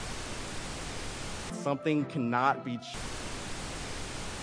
Monkey Bouton sonore